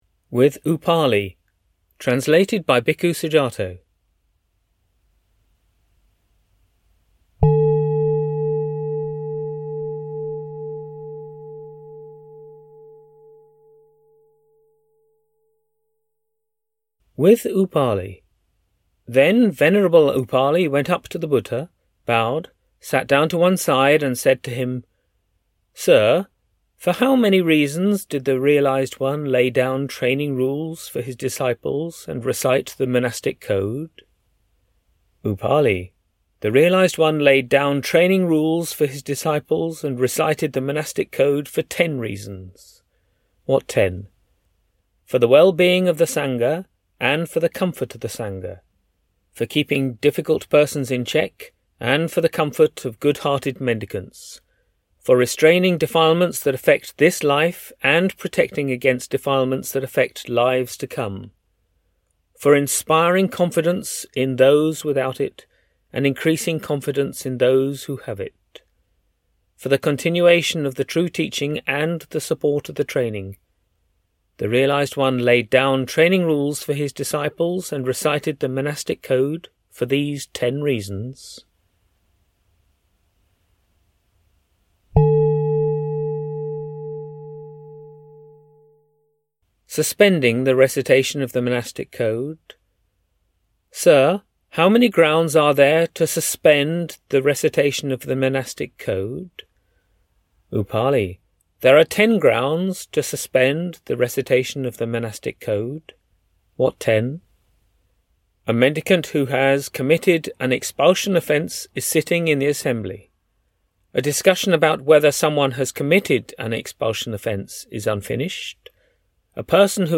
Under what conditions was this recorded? A selection of English translations of the suttas from the Pali canon which have been professionally read and recorded.